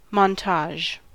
Ääntäminen
US : IPA : [mɒnˈtɑʒ]